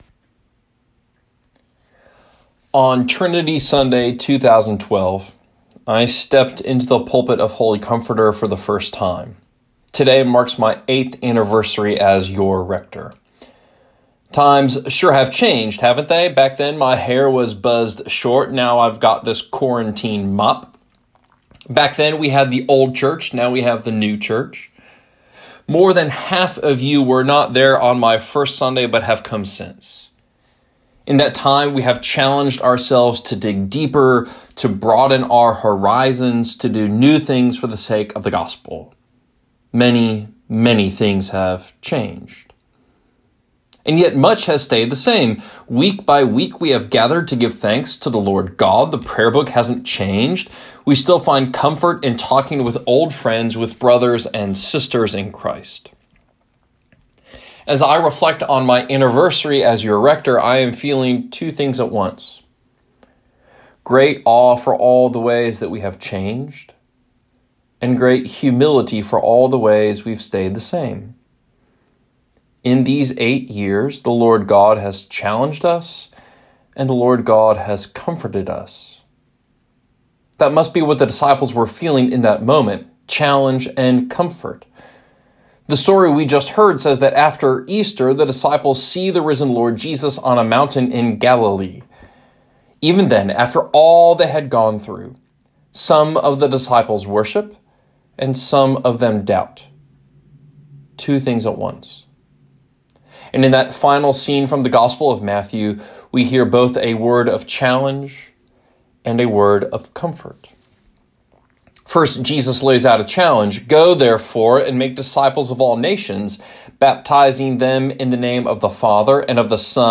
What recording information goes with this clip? Trinity Sunday